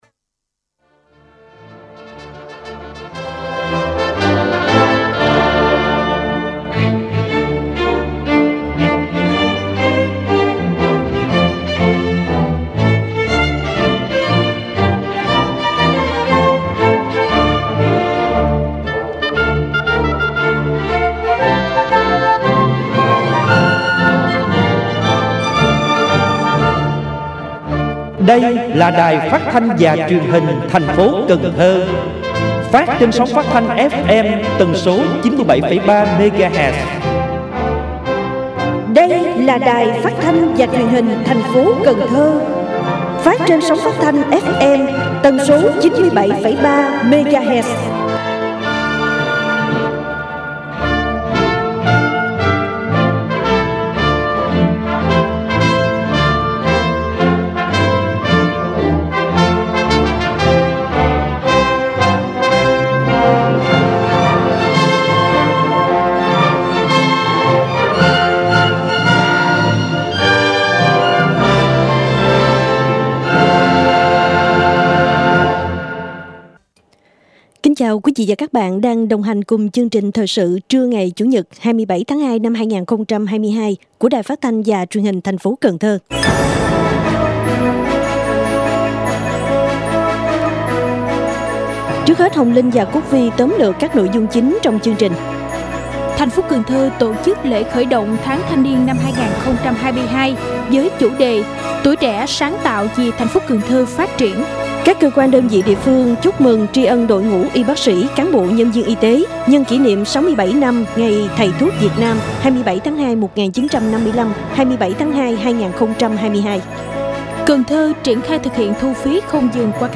Thời sự phát thanh trưa 27/02/2022